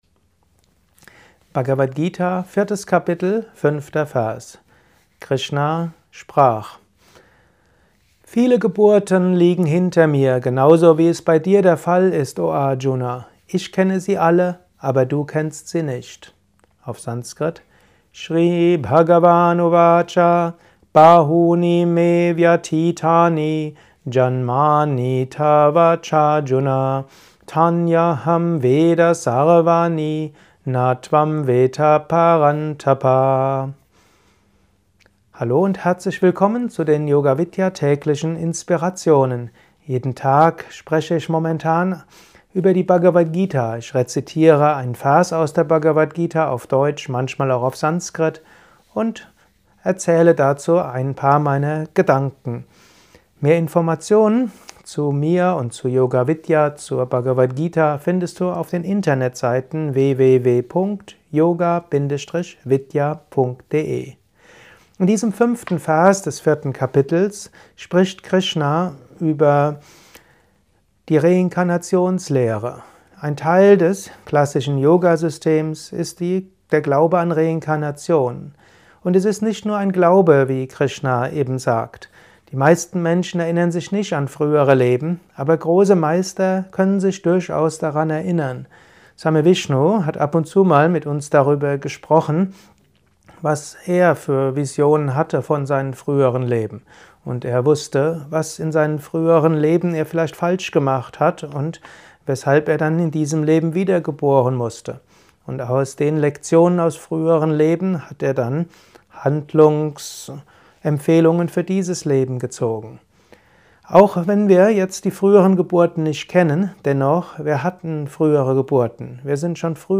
Vorträge über das, was Leben ausmacht Was geschieht nach dem Tod?